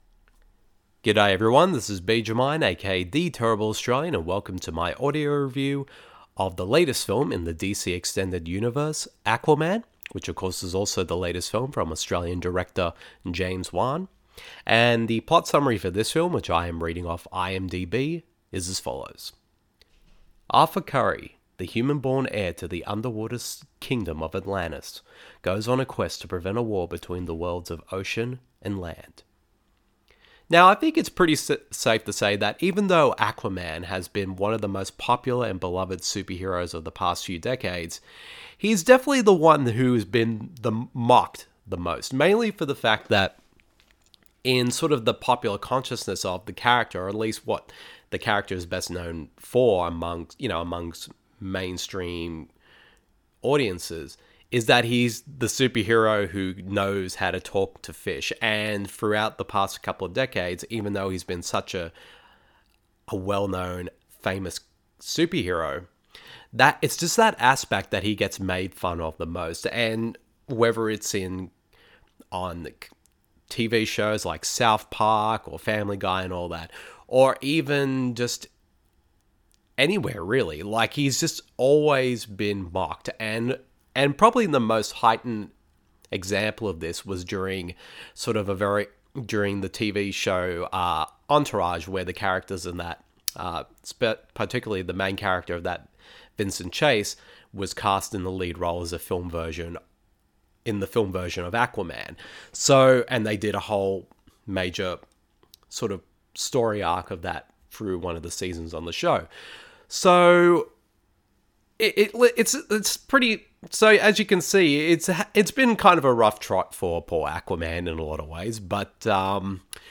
Now under the direction of Australian director James Wan (SAW, INSIDIOUS, THE CONJURING and FURIOUS 7), that has finally has arrived in the form of AQUAMAN. The following review of the film is in an audio format.